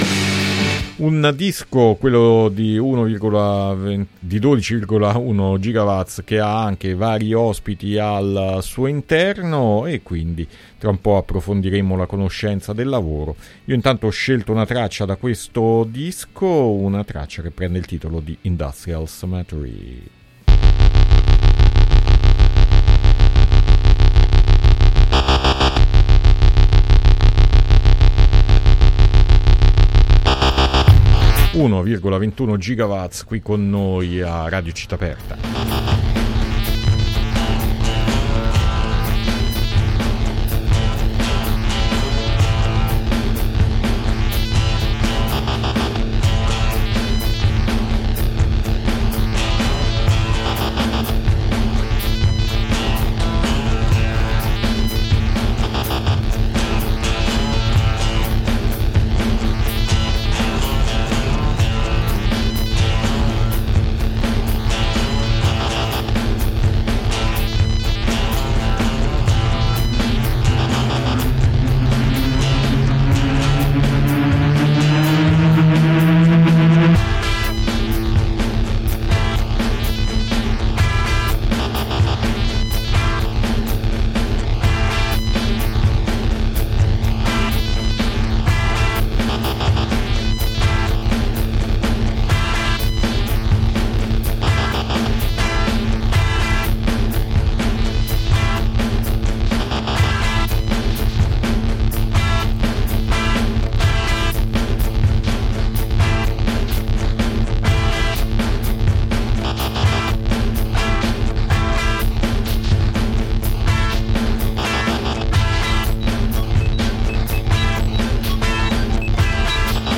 Intervista 1,21 Ggwtts